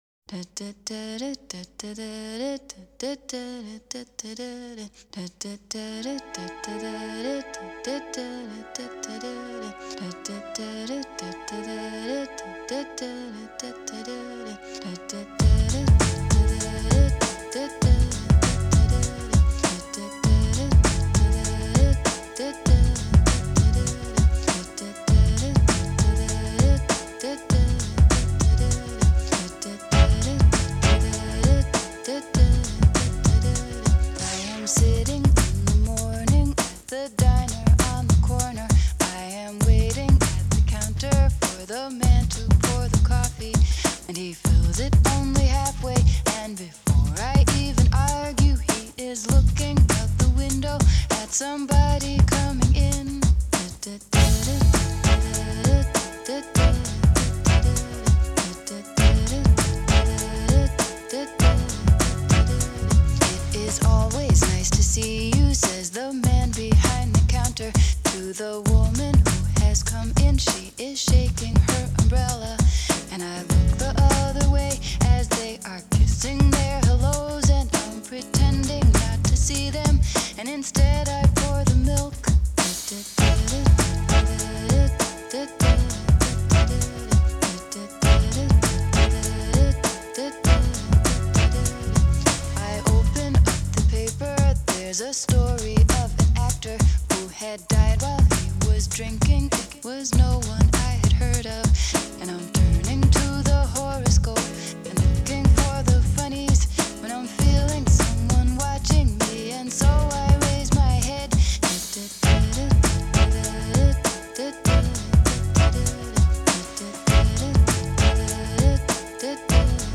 поп-музыка
хип-хоп